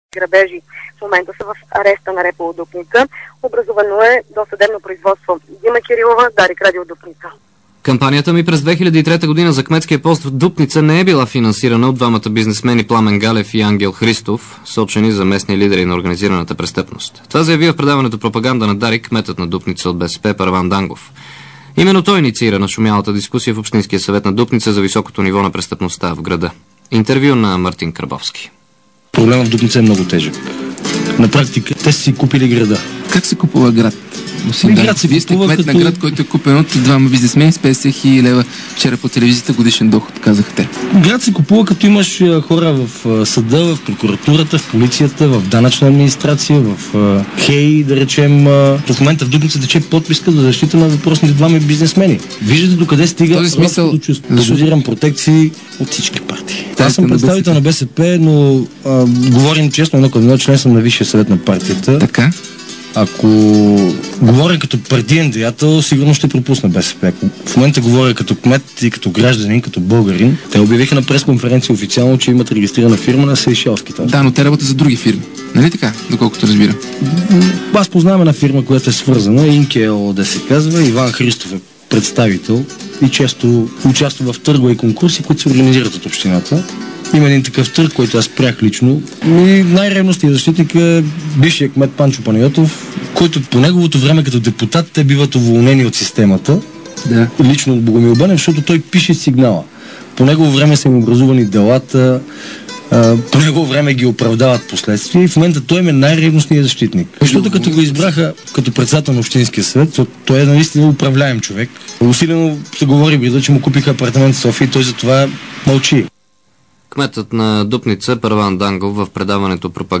DarikNews audio: Обедна информационна емисия – 28.05.2006